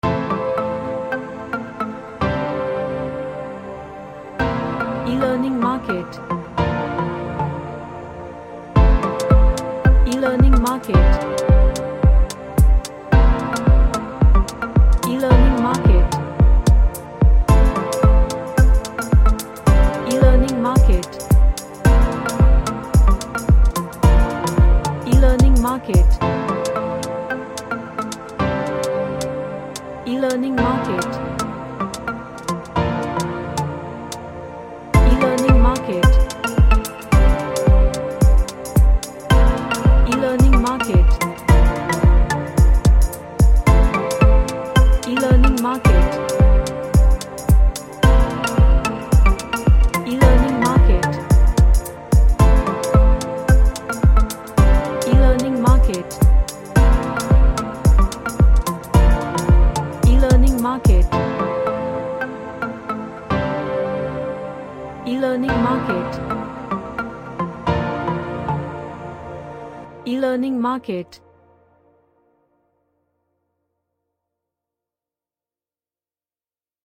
A track with groove and chords.
Chill Out